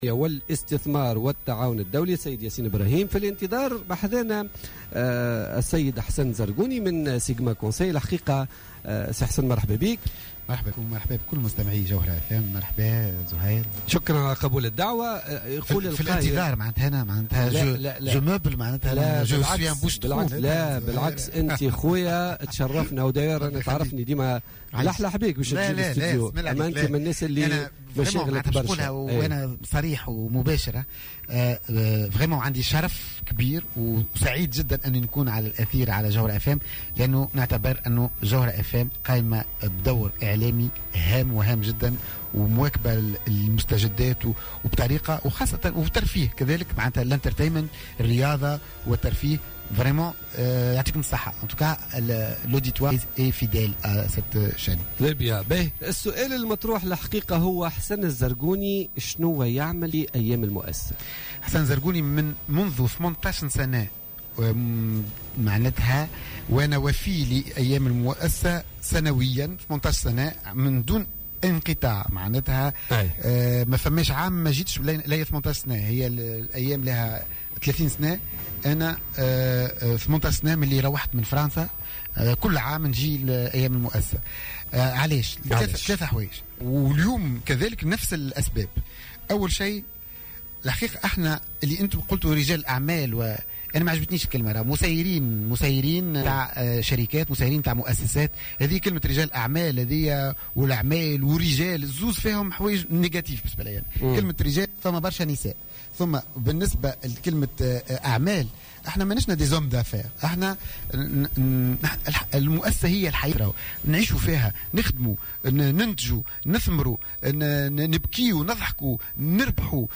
في مداخلة له في بوليتيكا